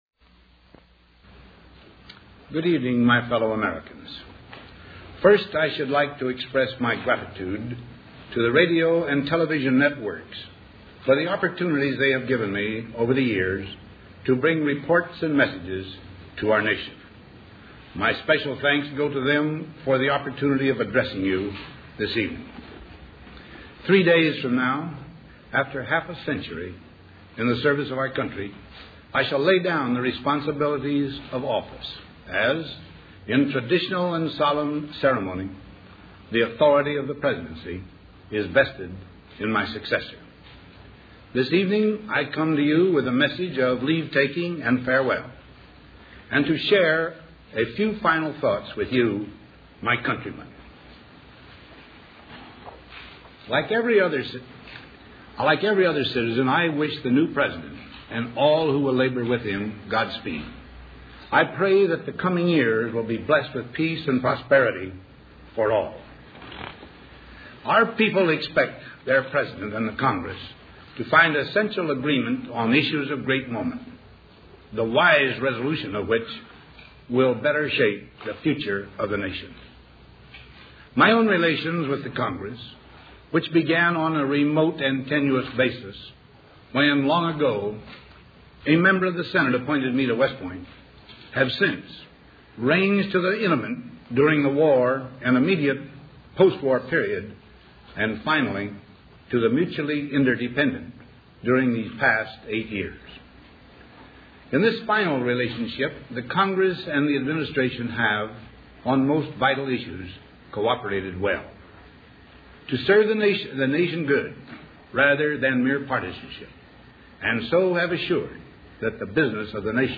Dwight D. Eisenhower: Farewell Address